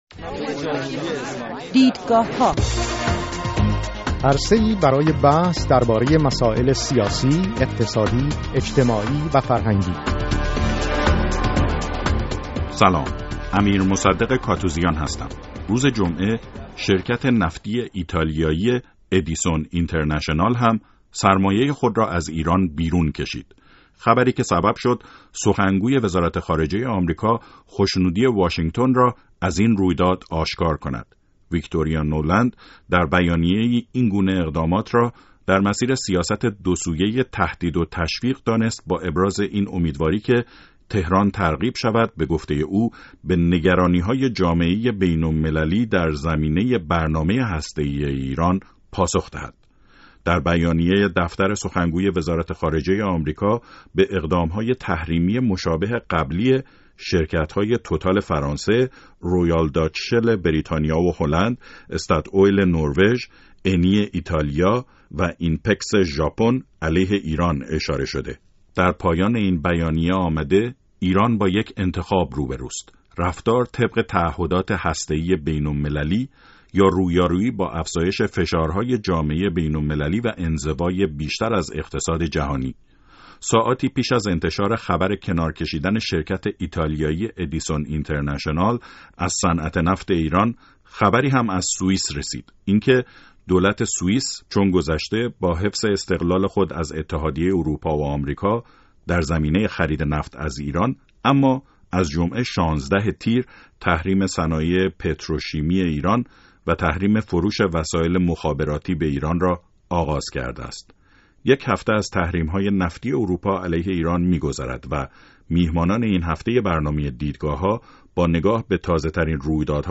مناظره: پیامد تحریم‌های جدید نفتی، بد یا خوب؟
یک هفته پس از اعمال تحریمهای جدید نفتی اتحادیه اروپا علیه ایران، سه کارشناس در برنامه «دیدگاهها» بر سر نتایج این تحریمها به مناظره نشسته اند.